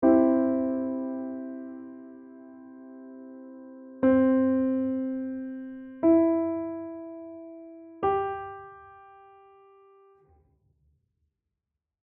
Un buon esercizio è suonare un accordo al pianoforte e cantare la nota fondamentale, poi le altre note dell’accordo (Do, Mi, Sol per un Do maggiore).
🎧 Ascolta qui l’esercizio:
accordo.mp3